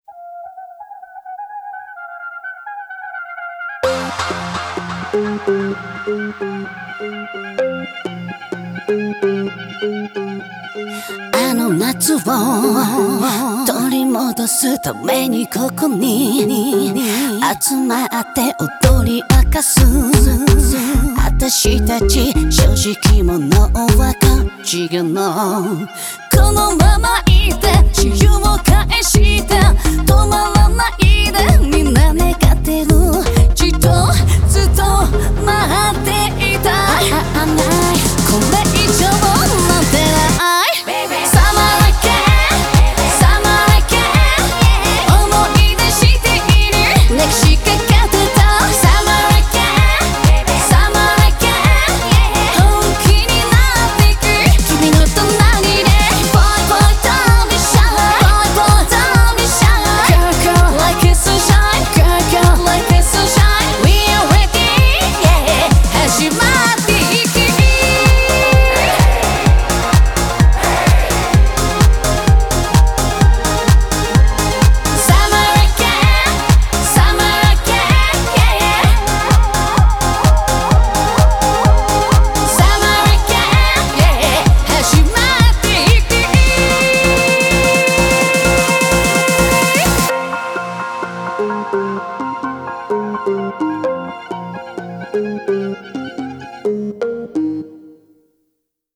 BPM63-127